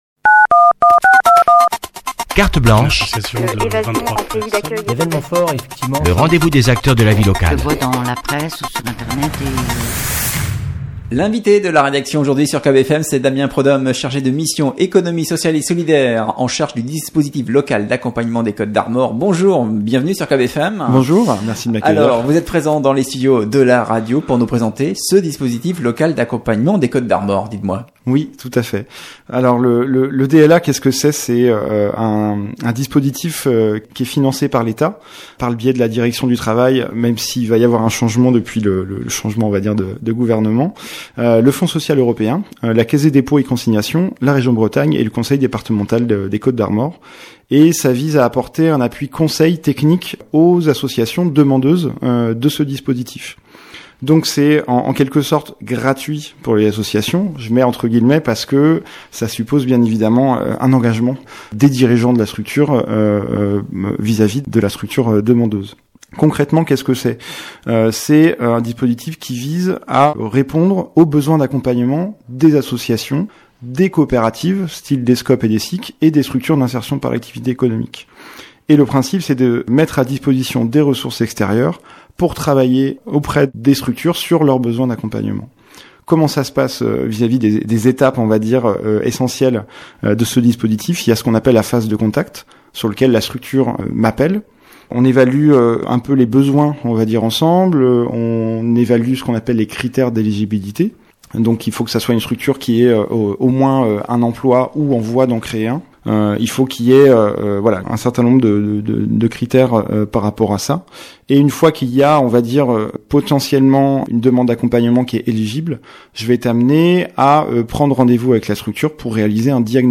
Cette entrée a été publiée dans Interviews.